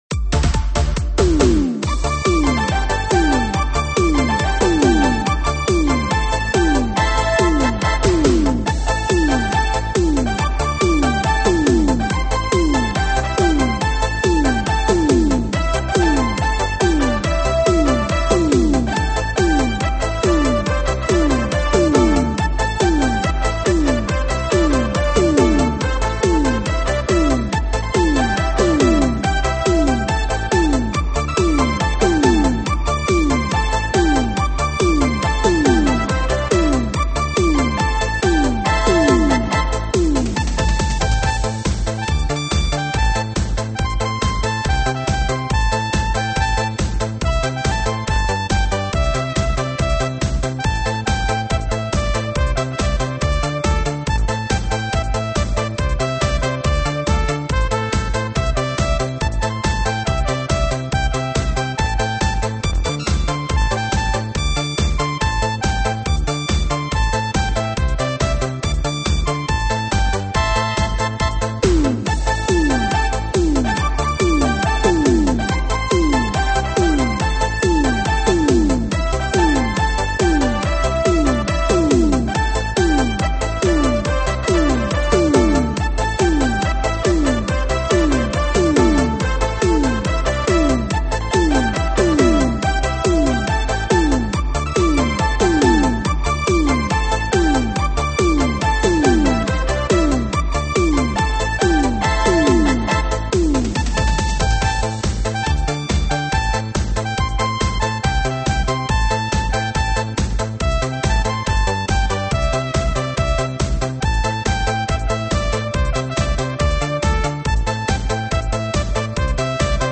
慢摇舞曲